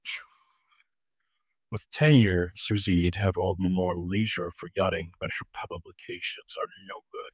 indic-languages text-to-speech voice-cloning